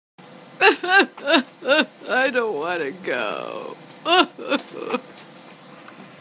Play Chatterpix Stump Crying I Don’t Wanna Go - SoundBoardGuy
chatterpix-stump-crying-i-dont-wanna-go.mp3